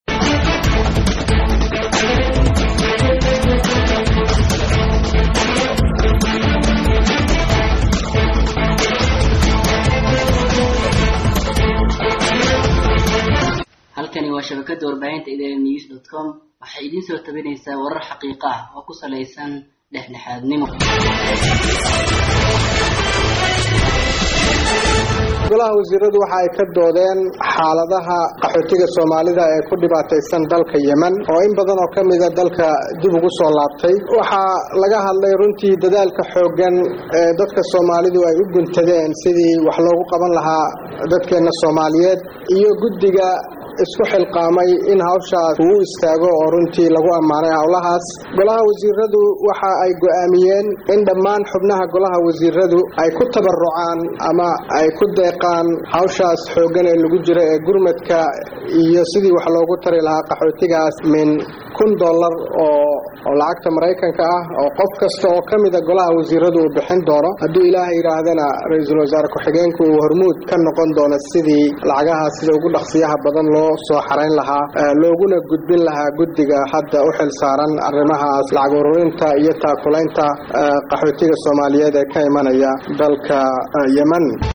Ridwaan Xaaji C/weli Afhayeenka Xukuumadda ayaa kedib markuu soo Idlaaday Kulanku Wariyeyaasha la hadlay ayaa sheegay in Xukuumaddu ay qaadatay Mas’uuliyadda gaar ah, taasoo ay Lacagtaas ku uruurineyso kuna dhiibeyso soo Celinta dadkeeda.